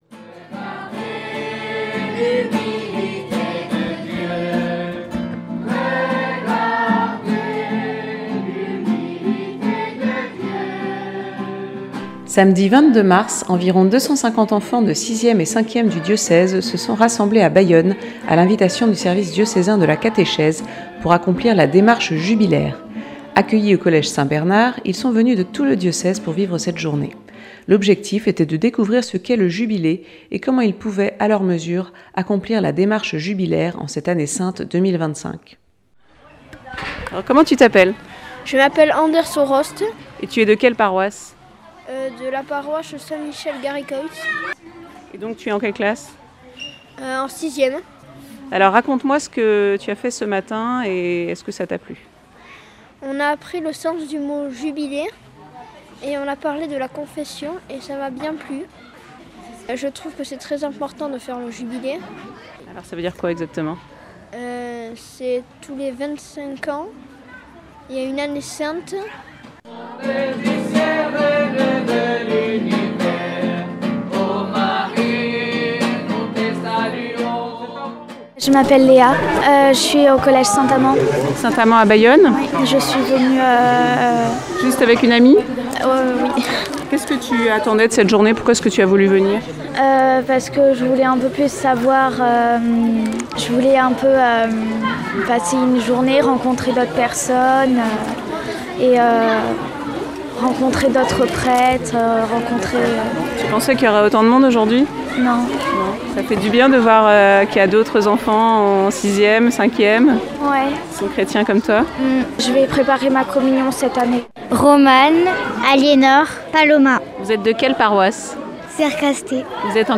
Le 22 mars 2025, environ 250 enfants de 6ème-5ème se sont rassemblés à Bayonne. Reportage.